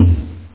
DRUM6.mp3